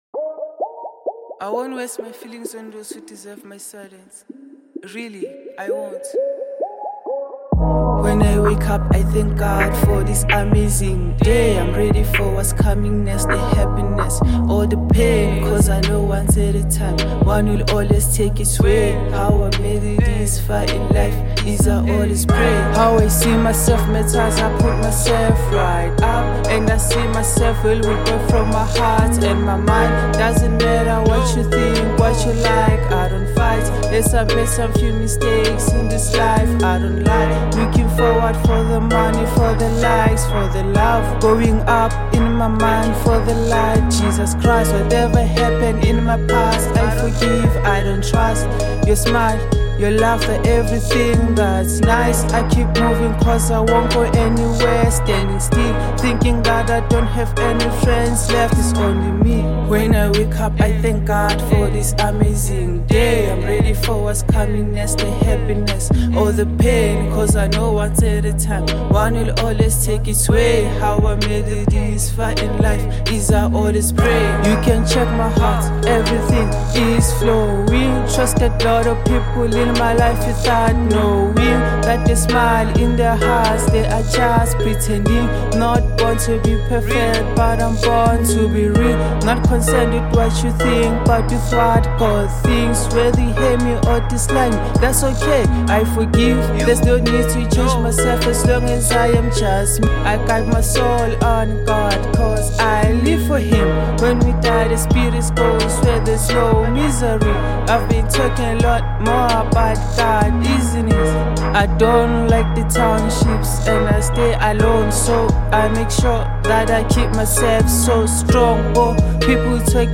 trap and hip-hop